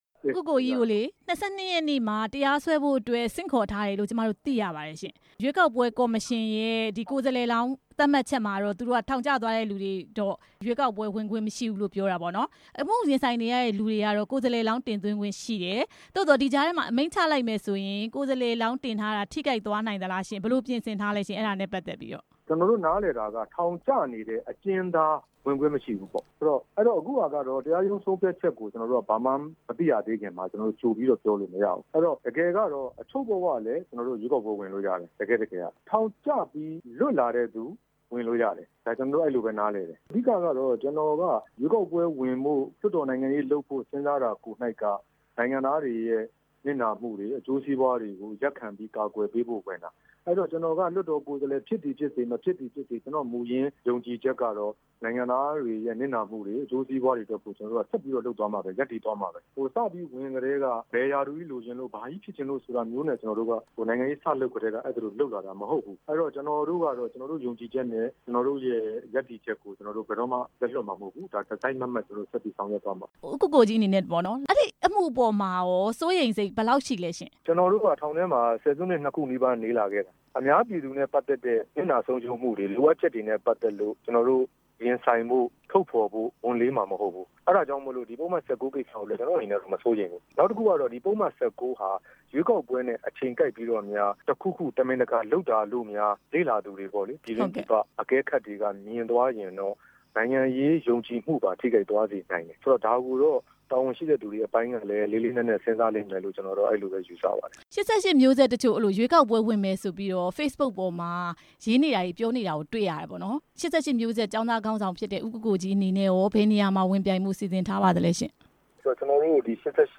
ဦးကိုကိုကြီး ကို မေးမြန်းချက်